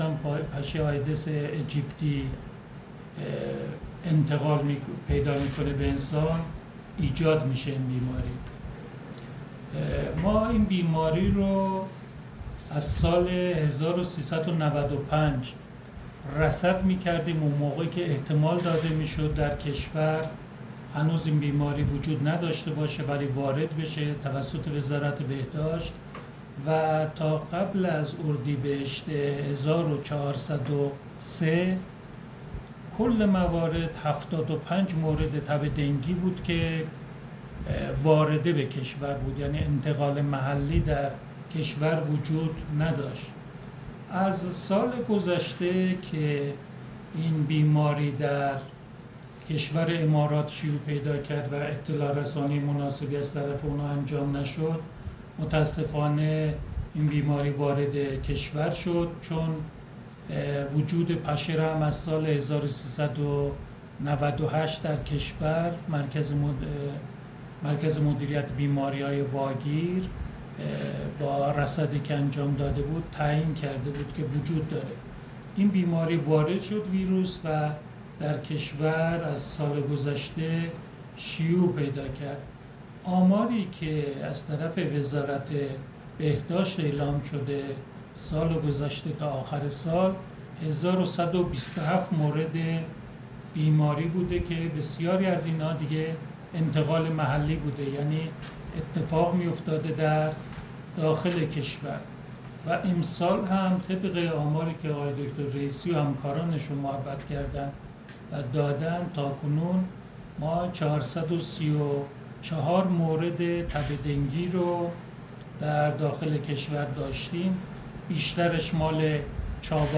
AudioCutter_مصاحبه تب دنگی(3).mp3